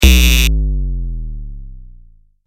دانلود آهنگ خطا 3 از افکت صوتی اشیاء
دانلود صدای خطا 3 از ساعد نیوز با لینک مستقیم و کیفیت بالا
جلوه های صوتی